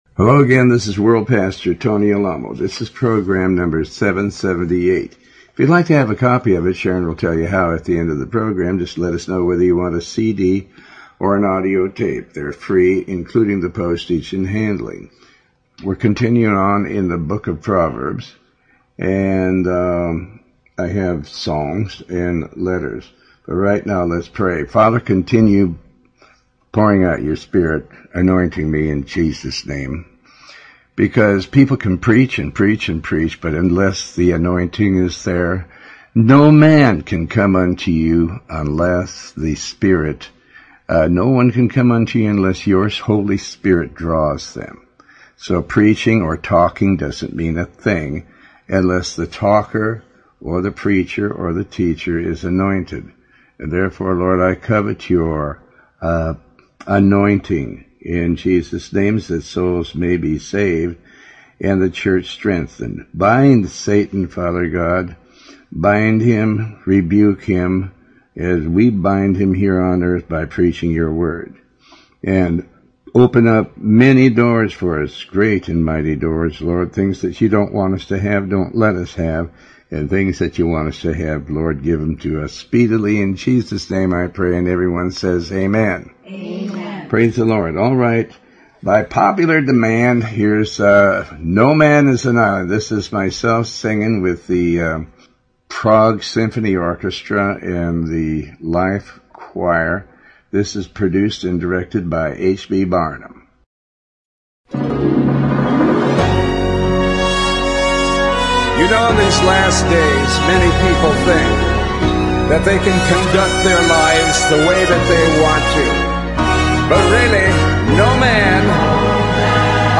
Talk Show Episode, Audio Podcast, Tony Alamo and The government of God is not a liar, God can not lie.